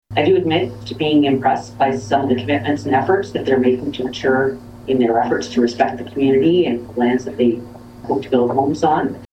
feb-24-lisa-mcgee-mayors-report-2.mp3